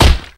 kick8.ogg